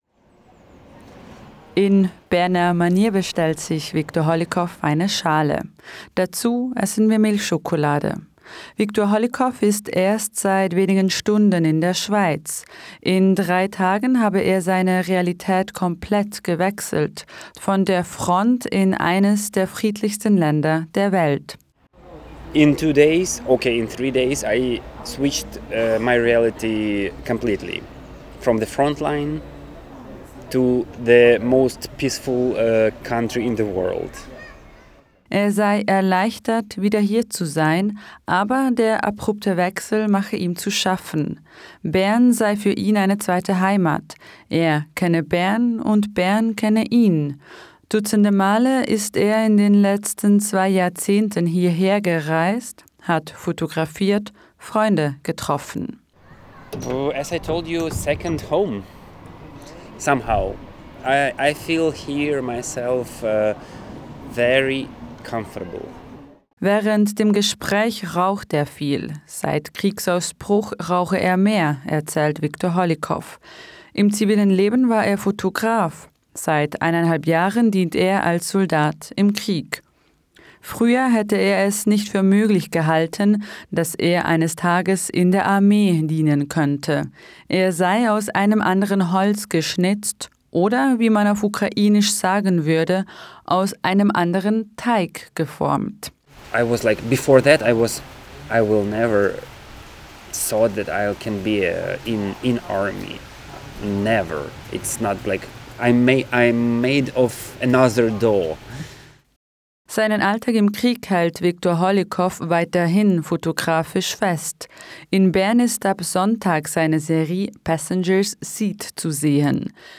Hört hier den vollständigen Audiobeitrag: